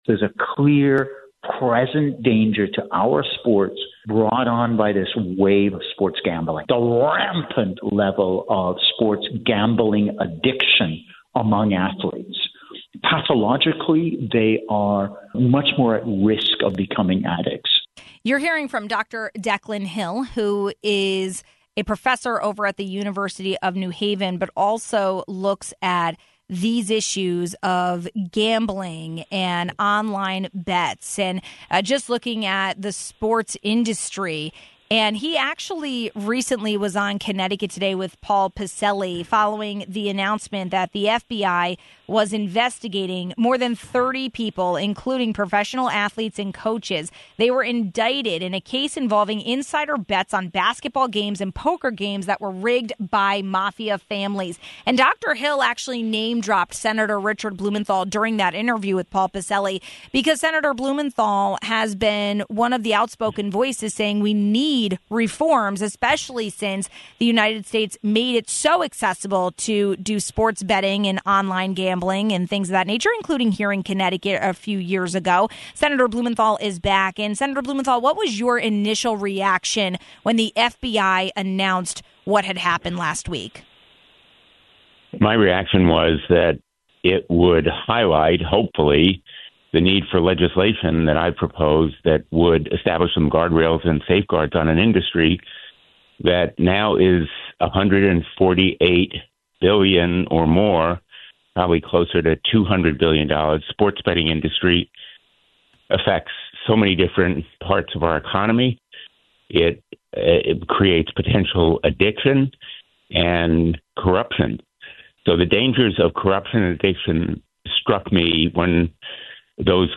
More than 30 people, including three former and current N.B.A. players, were indicted on Thursday in a case involving insider bets on basketball games and poker games rigged by Mafia families. We spoke with Senator Richard Blumenthal about his ongoing effort to pass reforms and get a better handling on gambling problems in America.